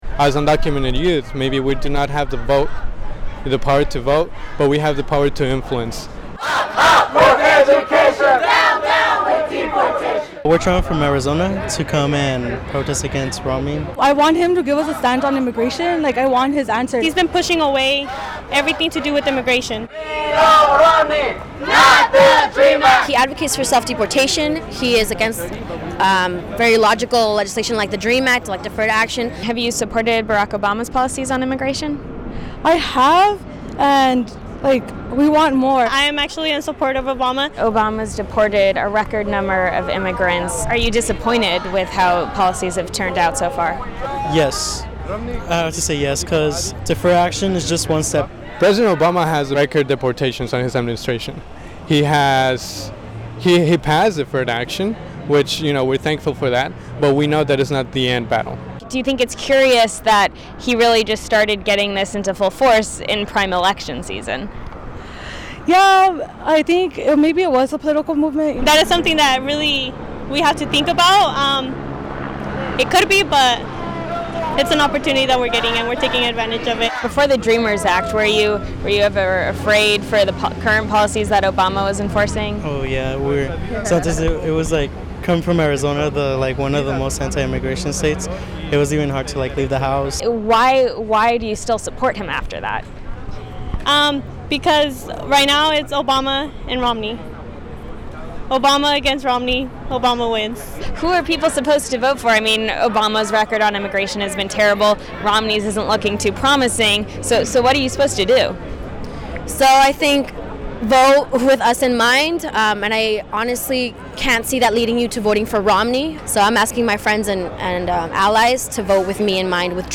ReasonTV caught up with some of the protesters in downtown Los Angeles, and questioned them on their views of Romney, Obama, and a realistic future for immigration policy.